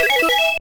The sound Gil makes in Namco Roulette in SSB4